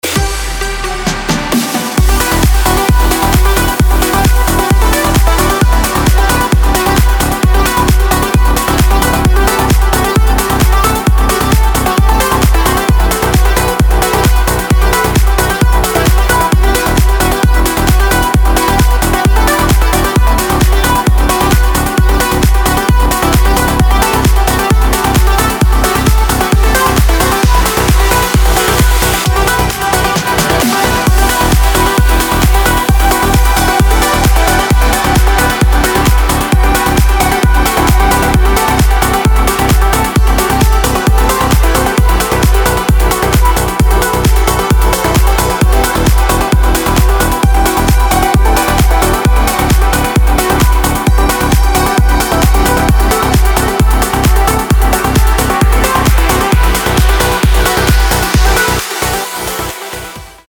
• Качество: 256, Stereo
громкие
мелодичные
dance
Electronic
EDM
электронная музыка
без слов
club
Trance